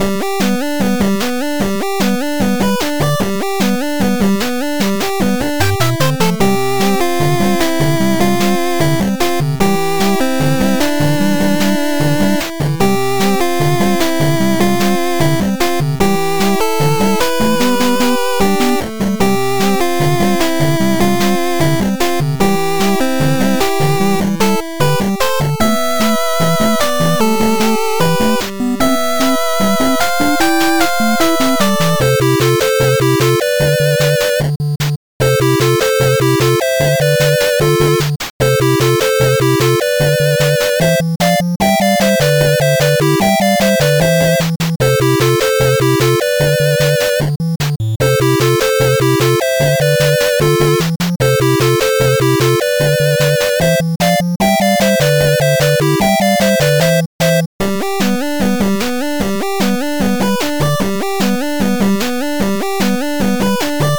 8bit music for action game.